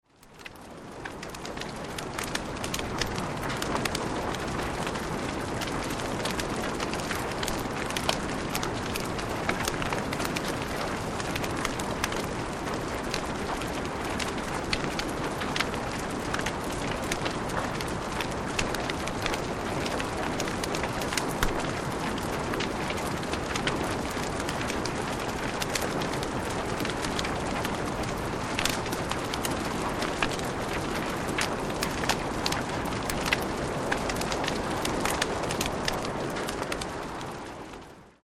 6 Stunden Kaminfeuer mit Regengeräuschen
Bei Geräuschaufnahmen sind diese ebenfalls in 44.1 kHz Stereo aufgenommen, allerdings etwas leister auf -23 LUFS gemastert.
44.1 kHz / Stereo Sound
Lautstärke: -23 LUFS
Hoerprobe-Kamin-Regen.mp3